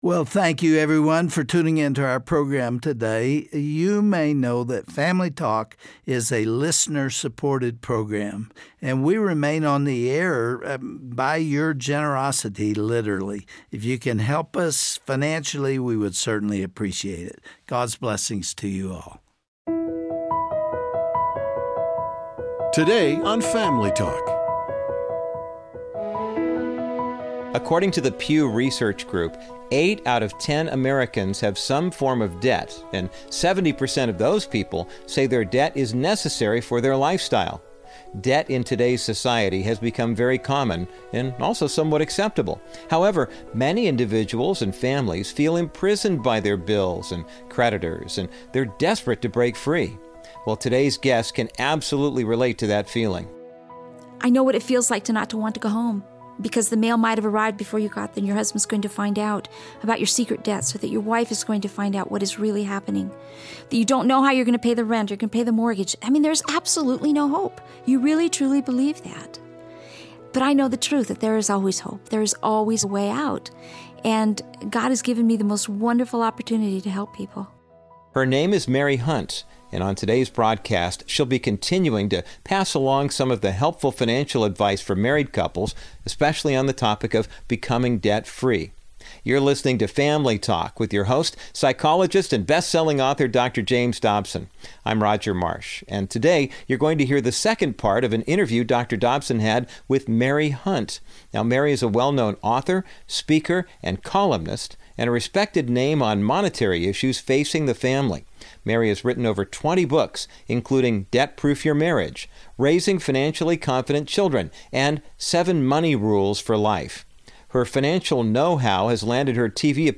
Listen to this practical and useful broadcast of Dr. James Dobsons Family Talk.